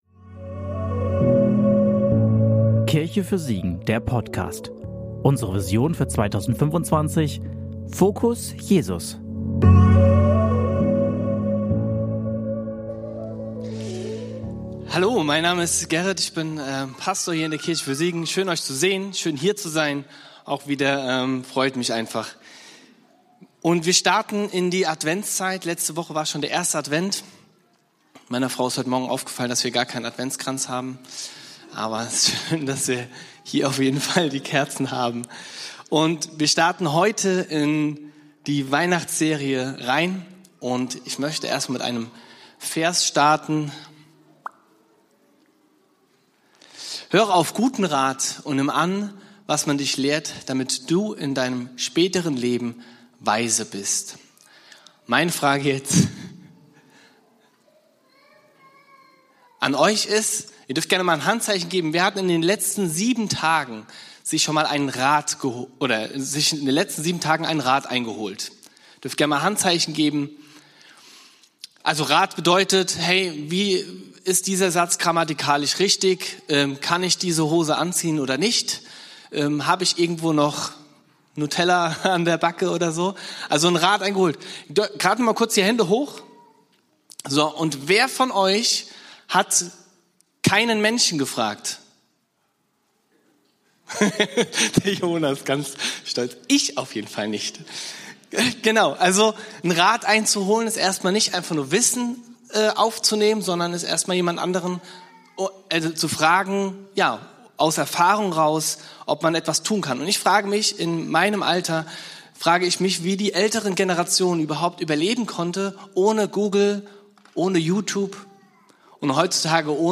Predigt vom 07.12.2025 in der Kirche für Siegen